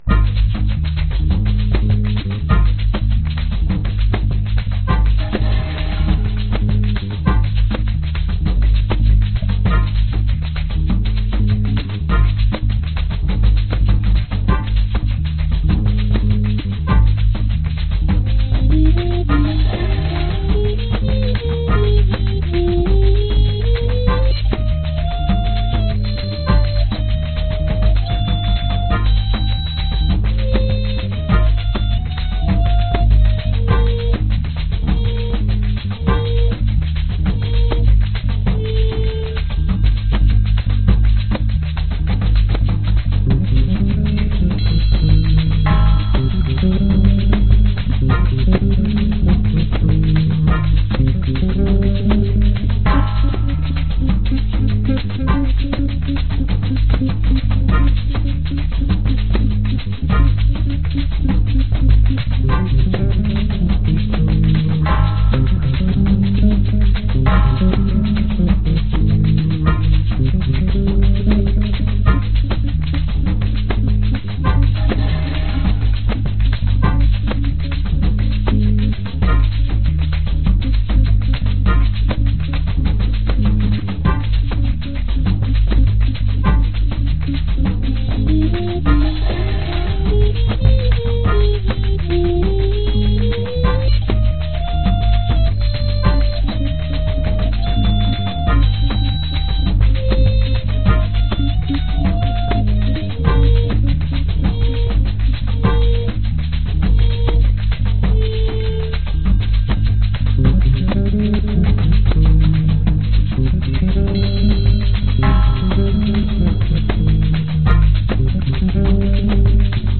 器乐 吉他 贝斯 小号 铜管 桑巴 波萨诺瓦 拉丁 爵士 爵士乐
快节奏